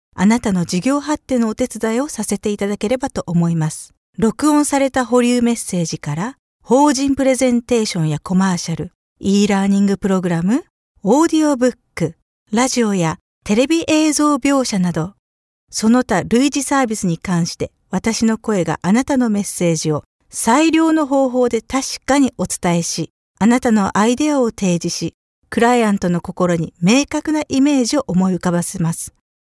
Japonca Seslendirme
KADIN SESLER